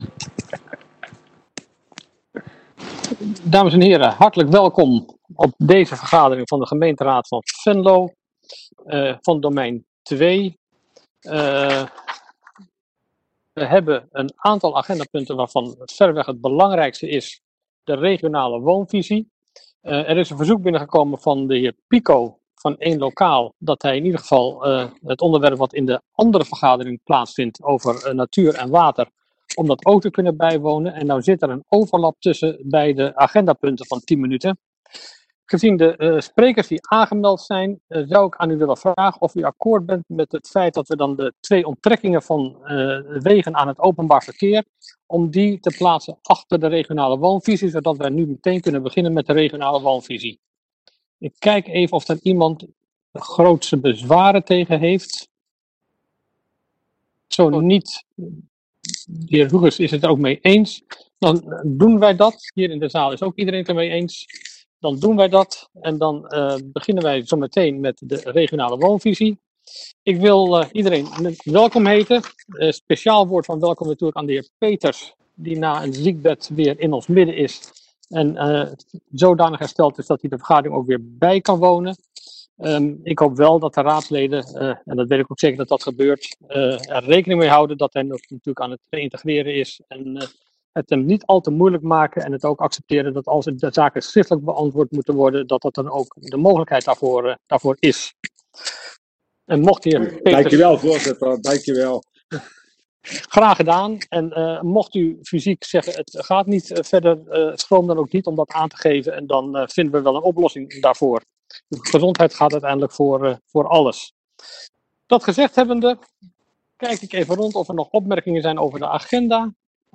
Oordeelsvormende raadsvergadering 09 september 2020 19:00:00, Gemeente Venlo
Sessievoorzitter: Harro Schroeder